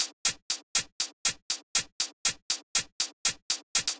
34 Hihat.wav